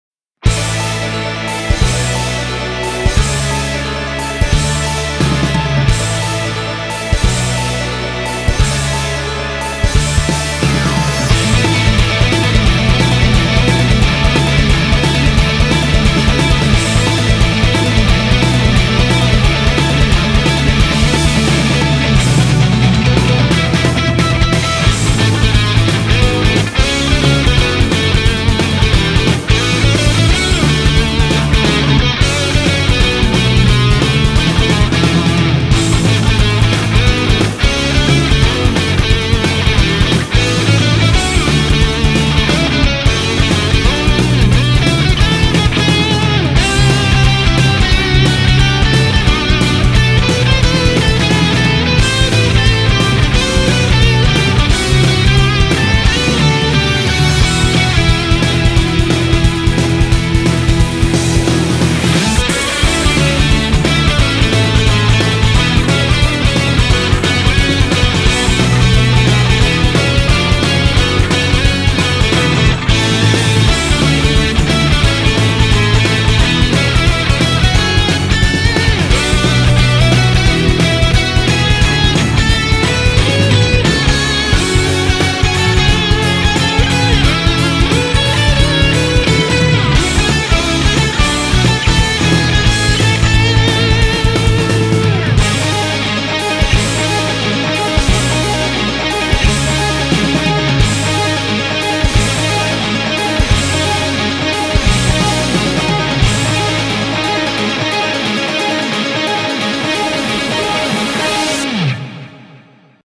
BPM177
Audio QualityPerfect (High Quality)
Comments[PROGRESSIVE ROCK]